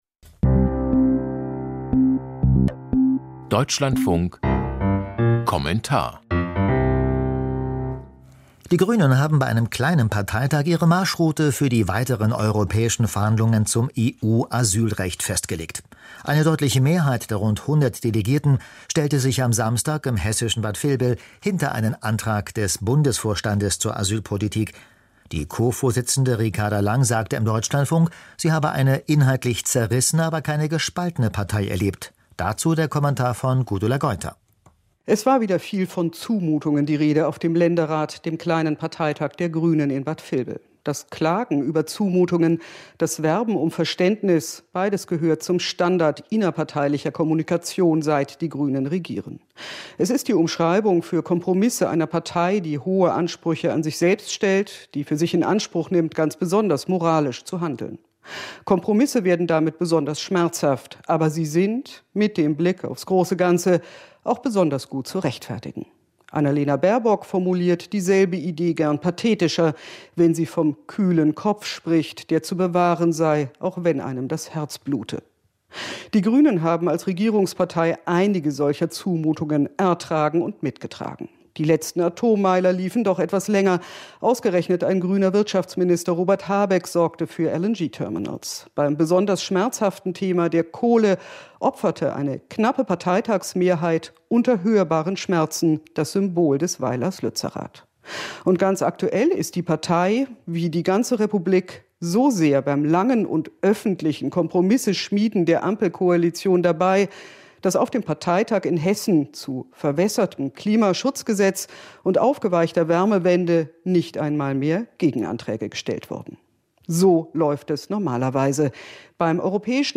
Kommentar zum Länderrat